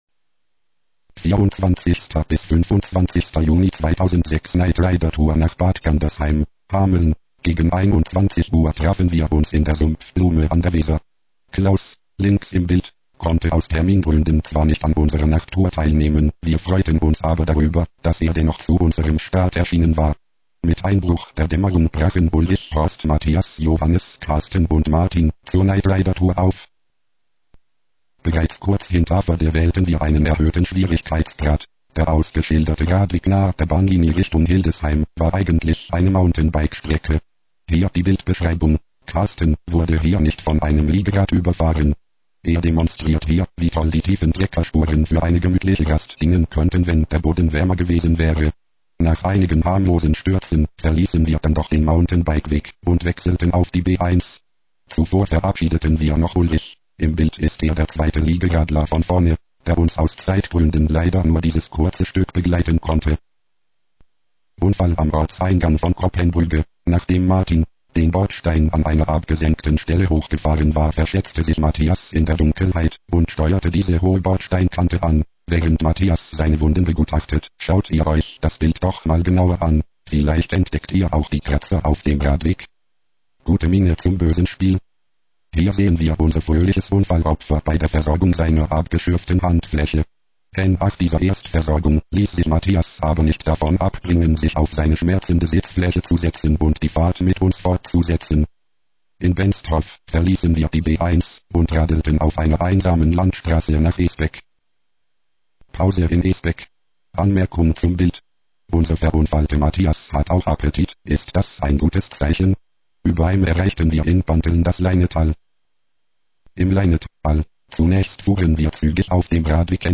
Weil dieser Bericht recht lang geworden ist, könnt ihr euch auch eine leicht abgeänderte Version von eurem Computer vorlesen lassen ( bericht.wav, 6,46 Min. 4 kB/s , 32 kBit/s )